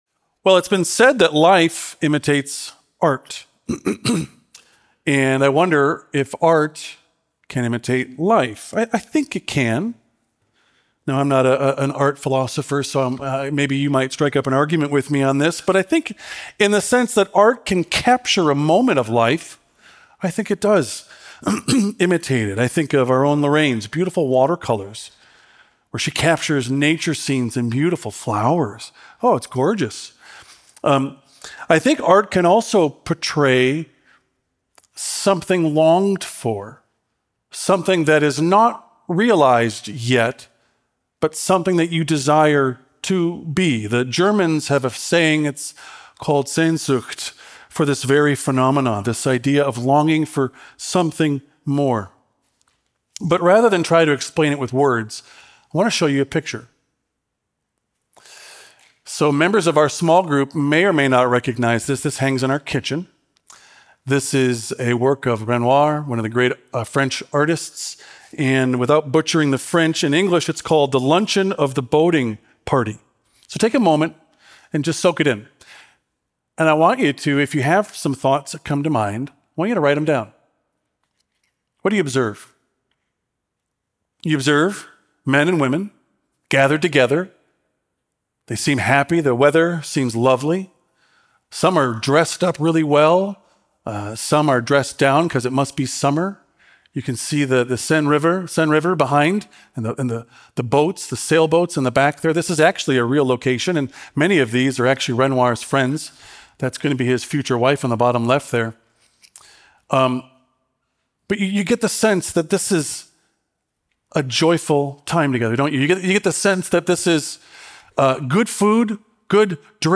A message from the series "1 Peter."